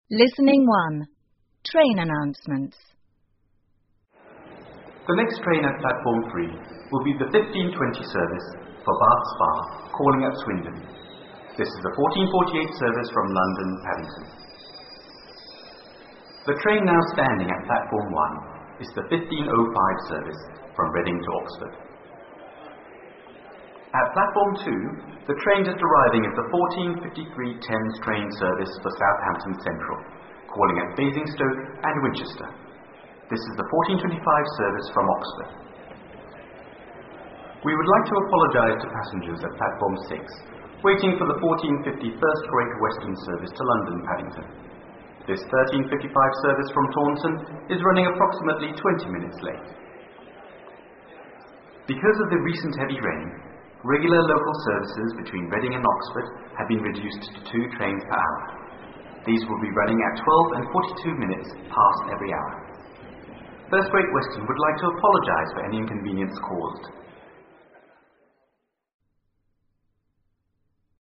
听见英国 UNIT 1 LISTENING 1 Train Announcements火车站广播 听力文件下载—在线英语听力室